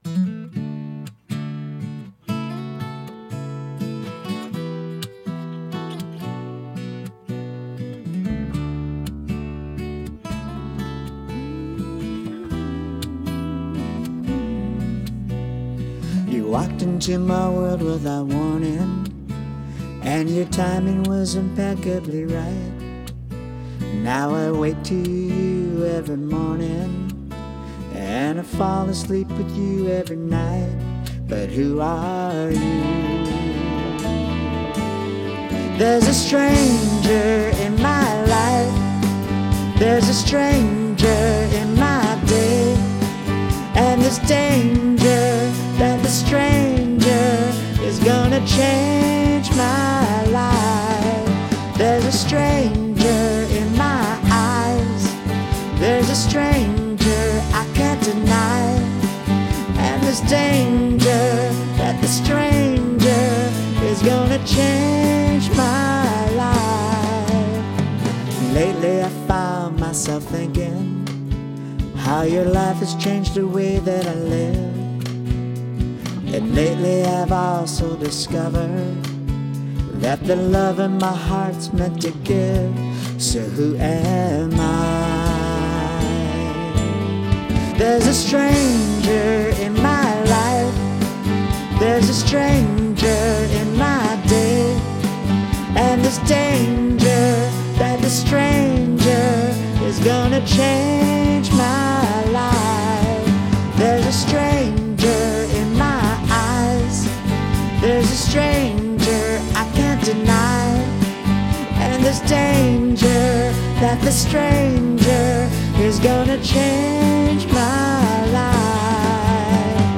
Key of D - Track with Reference Vocal